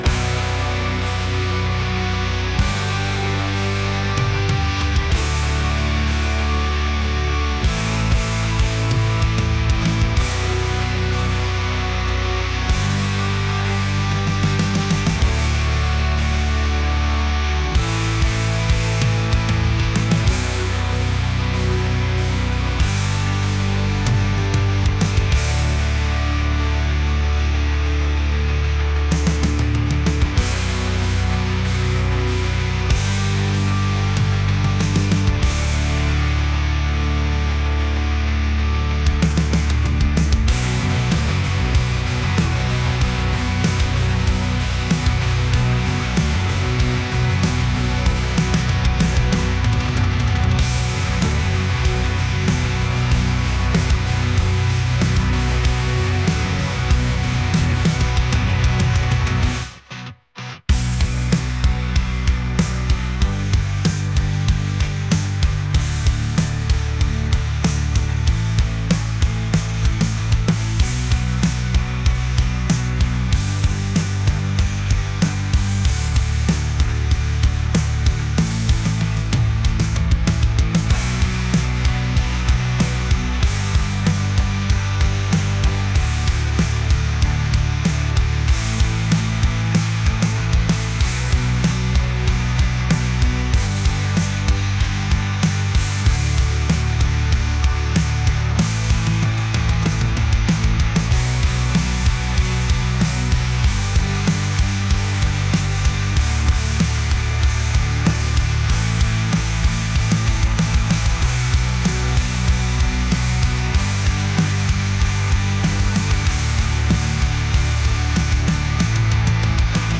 alternative | metal | heavy